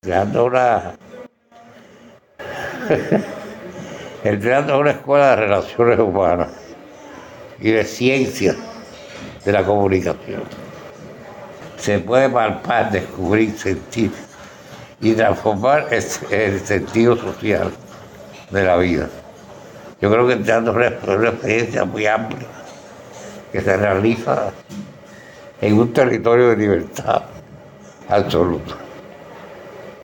Cultura Entrevista